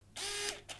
Звуки зума
Звук приближения зума